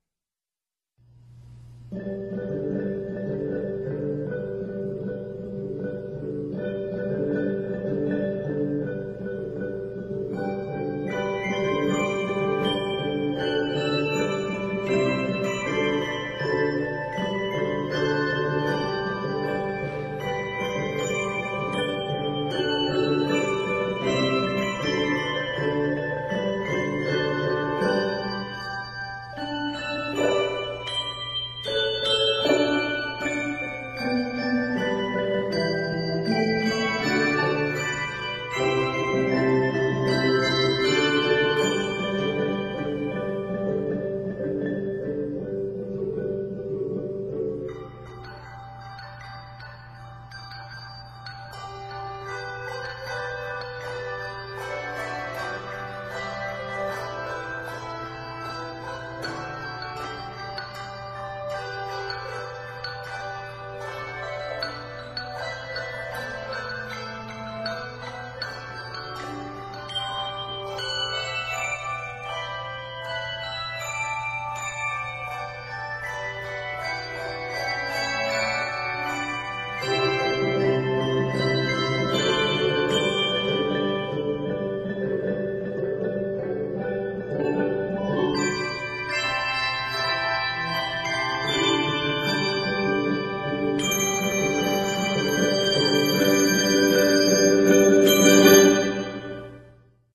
A rhythmic pattern (8th and 2 16th notes)
Much mallet work in the bass clef.